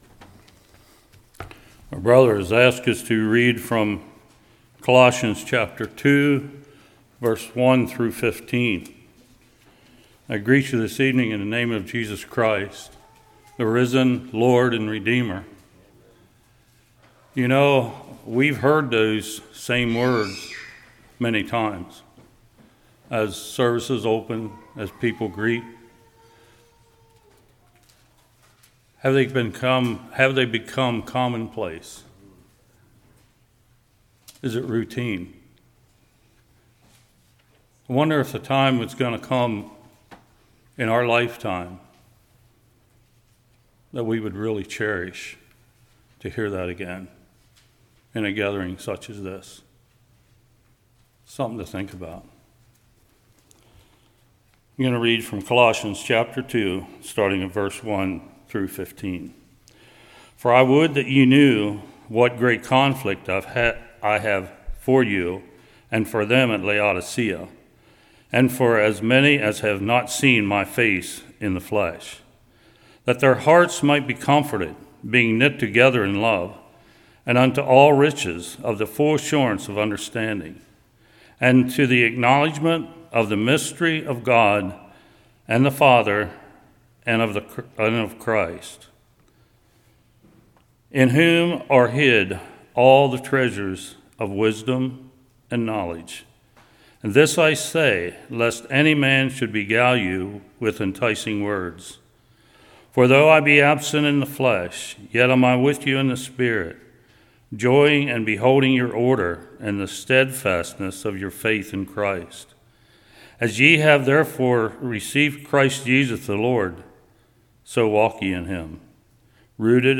Colossians 2:1-15 Service Type: Evening He Created All Thing The Power Of God Do We Survey The Cross?